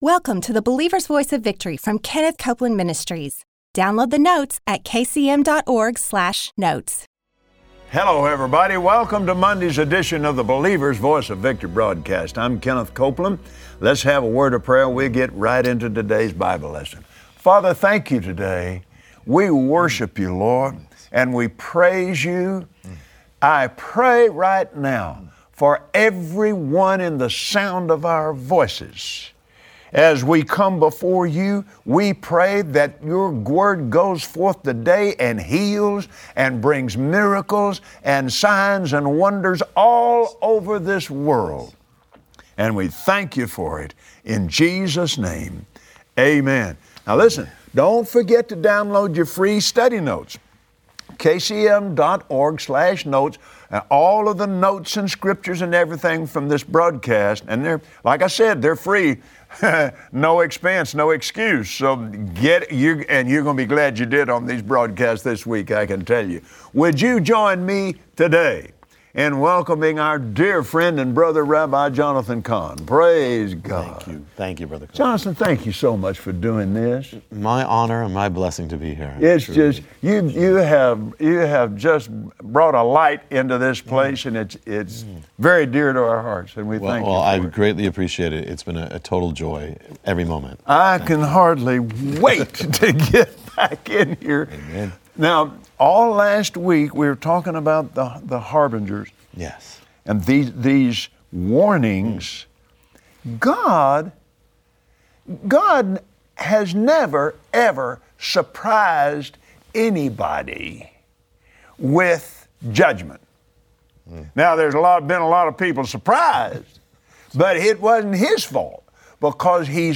Join Kenneth Copeland and his special guest, Rabbi Jonathan Cahn, today for insight into the majesty and the mercy of God.